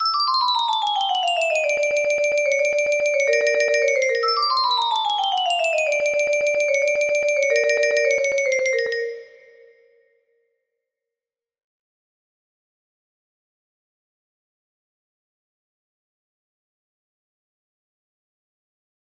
Untitled but united music box melody